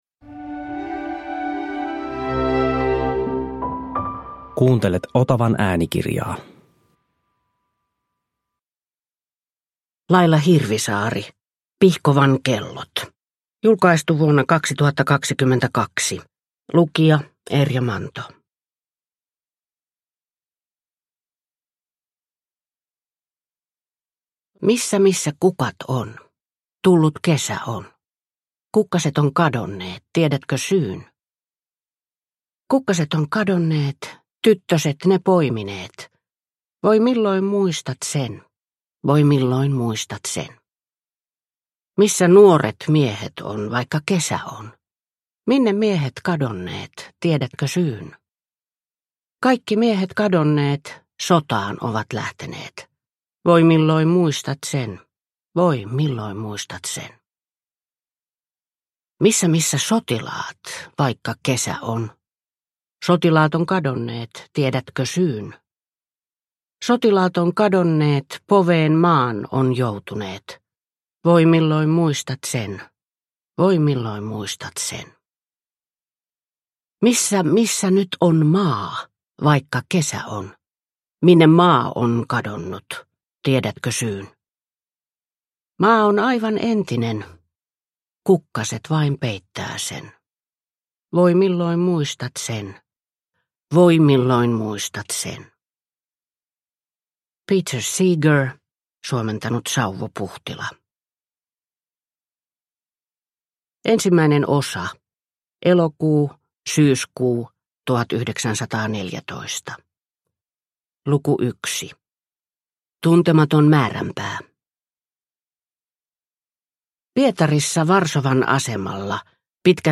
Pihkovan kellot – Ljudbok – Laddas ner